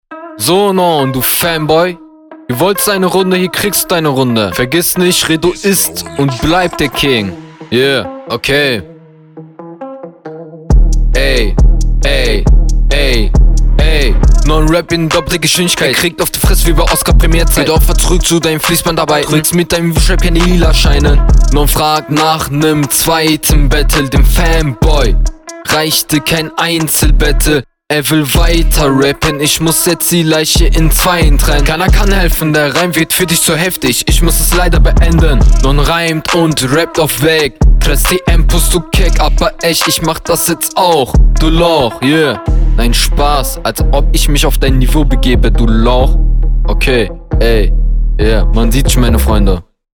trifft den takt aber sehr unsauber und unroutiniert man hört jeden cut raus außerdem cuttest …